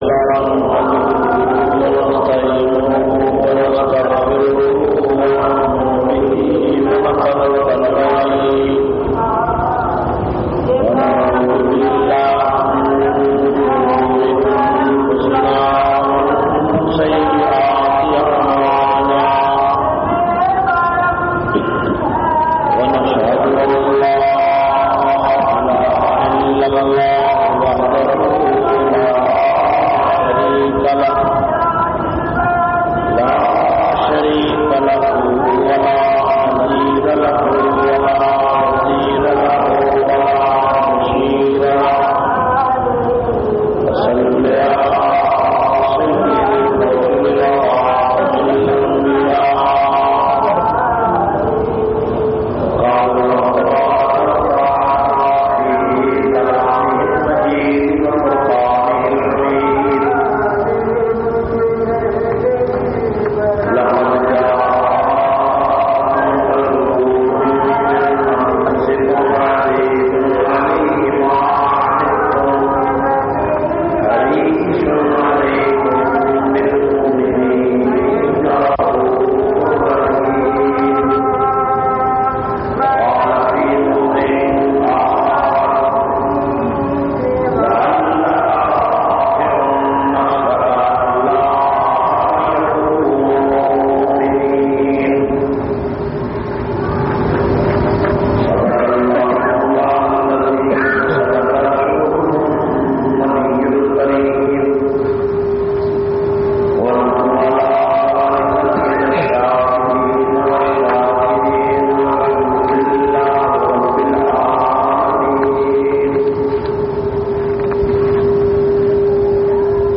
540- Aylan e Nabuwwat Jumma khutba Jamia Masjid Muhammadia Samandri Faisalabad.mp3